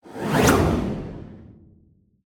menu-freeplay-click.ogg